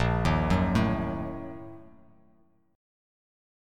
BbM7sus4#5 chord